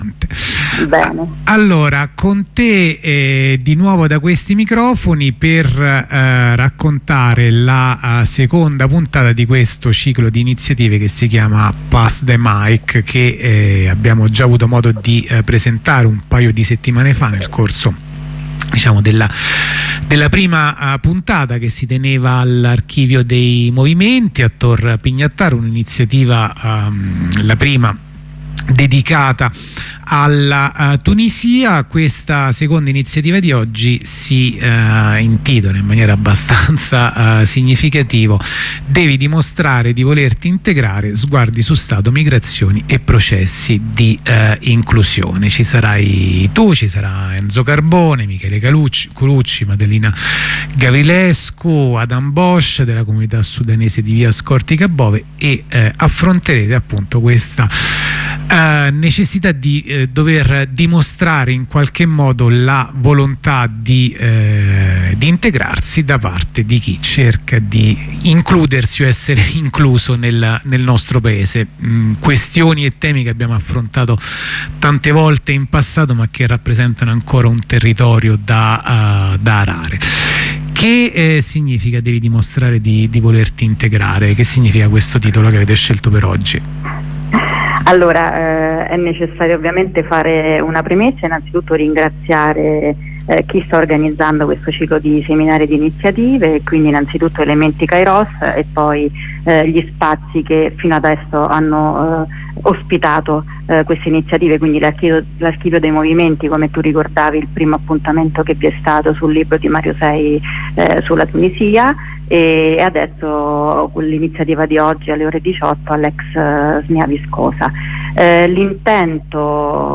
Corrispondenza con un compagno dei Cobas settore privato